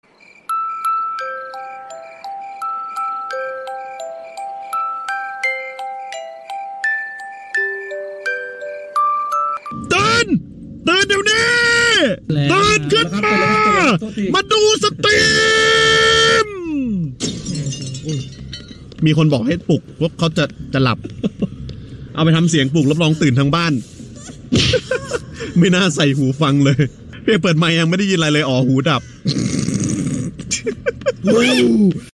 หมวดหมู่: เสียงเรียกเข้า
นี่เป็นเสียงนาฬิกาปลุกที่ได้รับความนิยมใน TikTok มาก